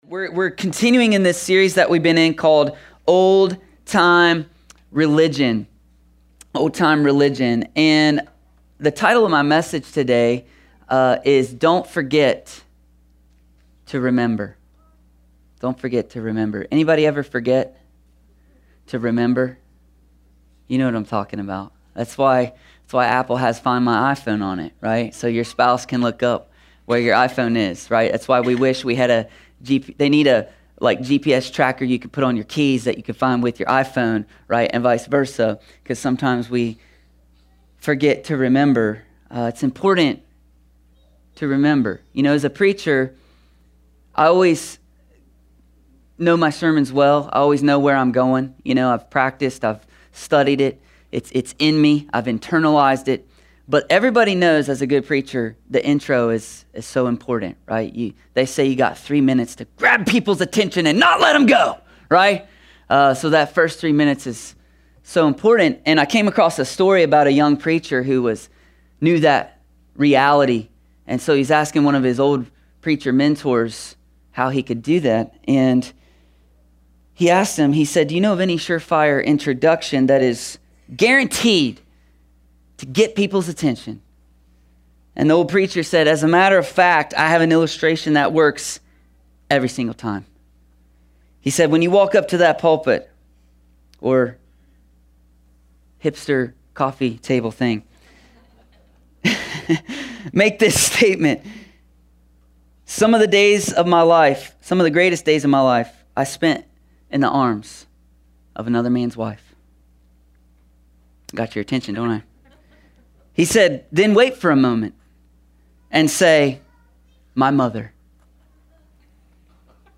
A sermon from the series “Old Time Religion.”…